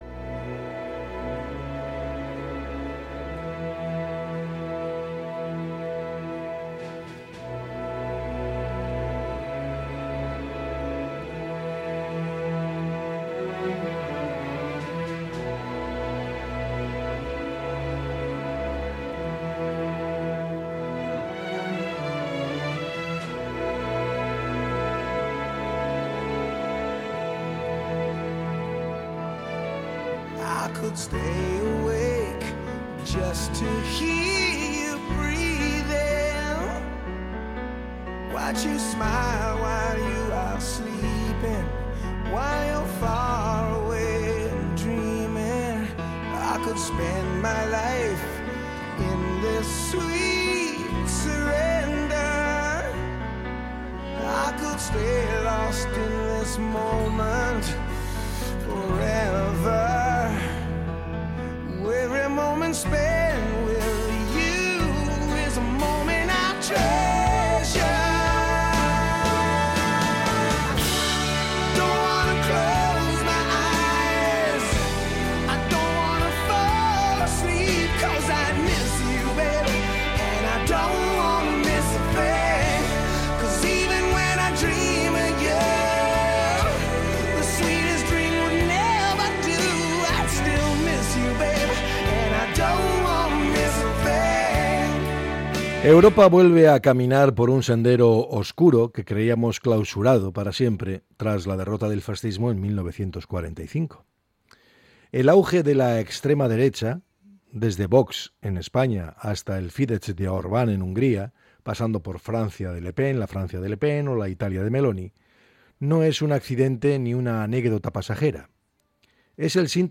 El comentario